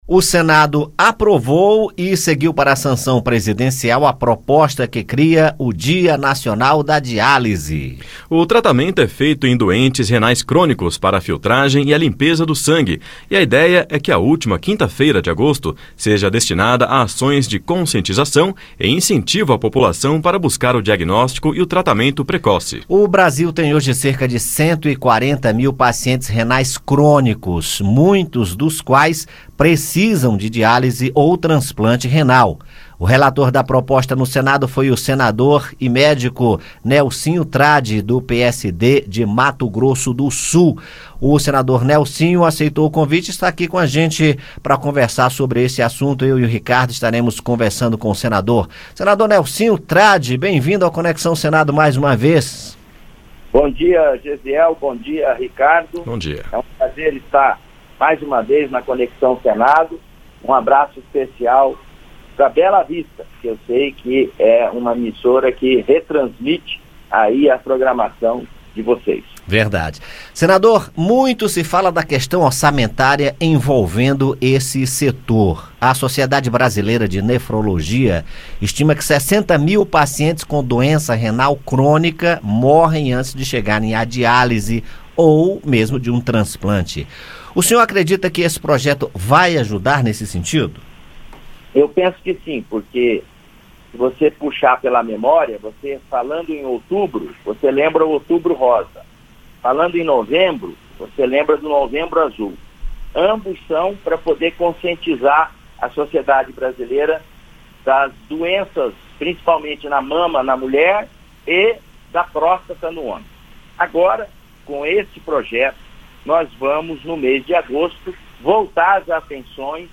O relator da proposta, senador Nelsinho Trad (PSD-MS), que também é médico, conversou com o Conexão Senado sobre a questão orçamentária que envolve o setor e o acesso aos tratamentos adequados.